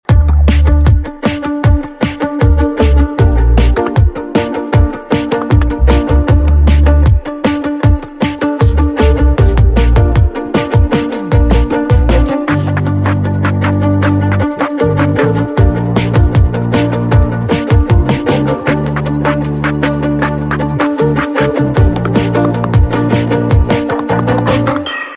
Beatbox X Violin